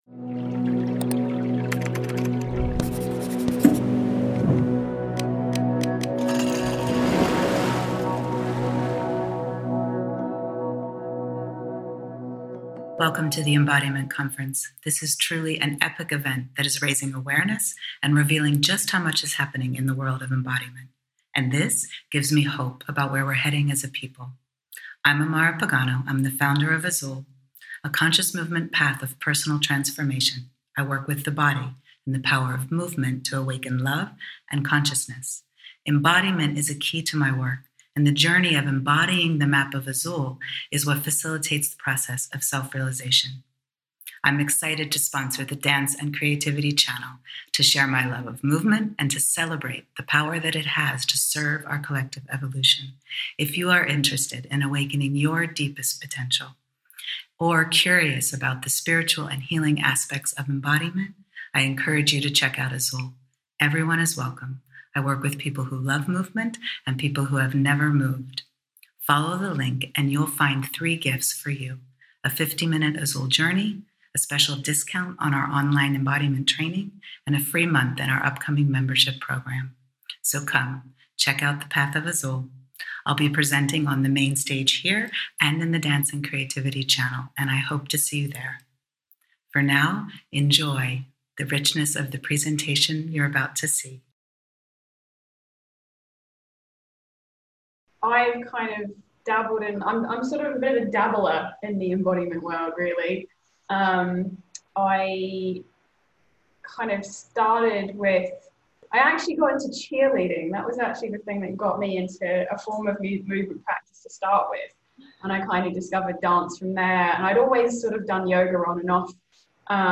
Likely soothing